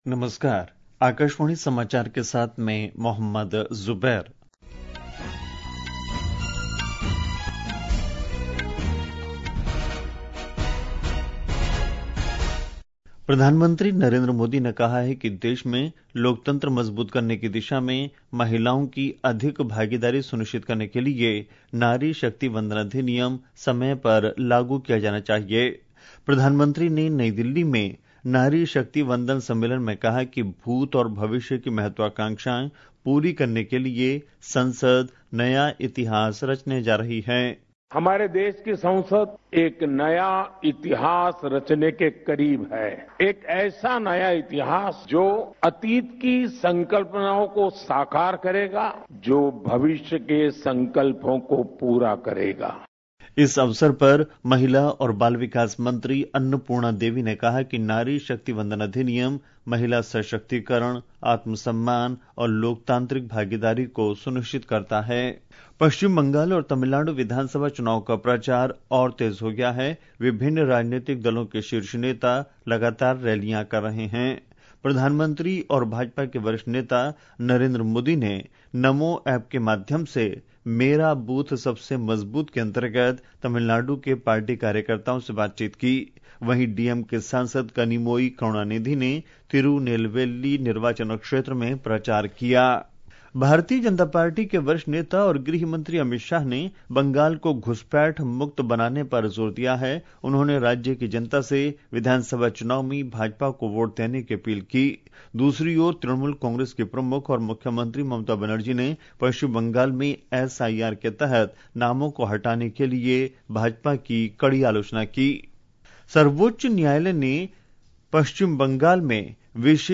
રાષ્ટ્રીય બુલેટિન
प्रति घंटा समाचार